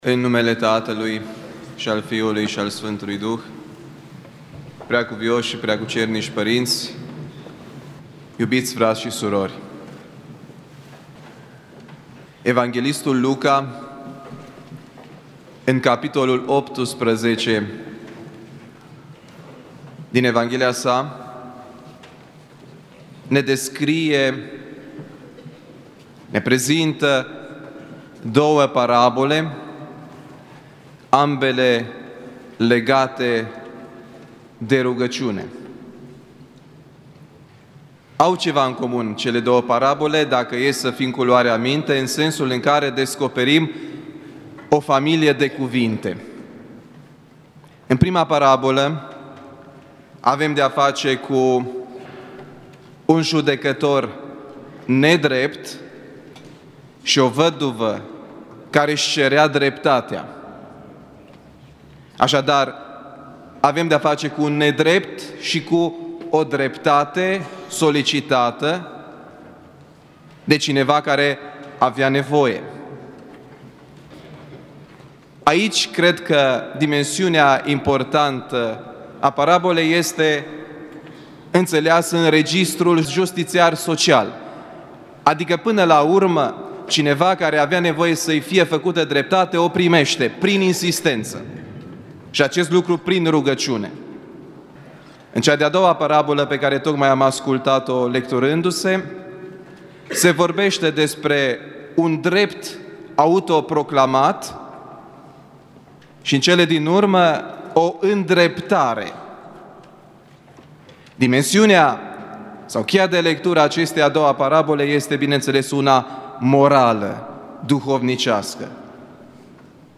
Predică la Duminica a 33-a după Rusalii (a Vameșului și a Fariseului)
Cuvinte de învățătură